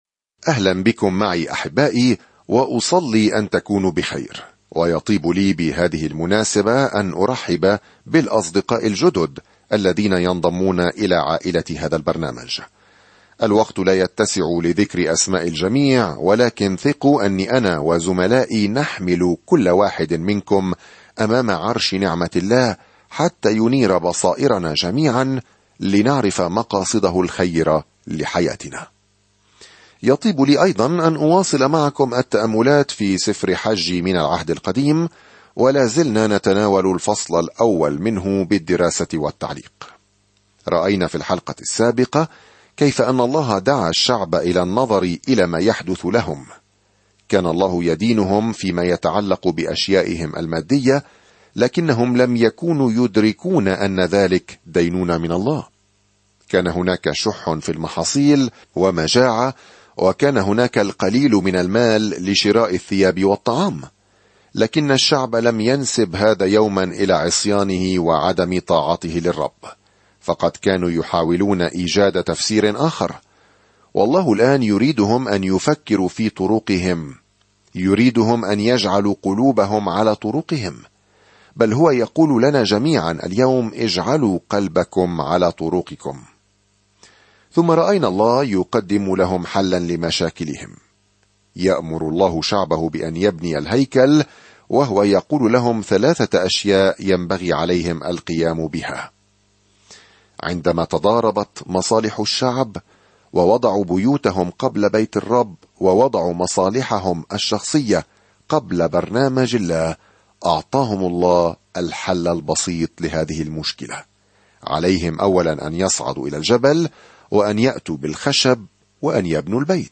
الكلمة حَجَّي 8:1-14 يوم 3 ابدأ هذه الخطة يوم 5 عن هذه الخطة إن موقف حجي "أنجز الأمر" يحث إسرائيل المشتتة على إعادة بناء الهيكل بعد عودتهم من السبي. سافر يوميًا عبر حجي وأنت تستمع إلى الدراسة الصوتية وتقرأ آيات مختارة من كلمة الله.